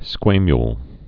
(skwāmyl, skwä-)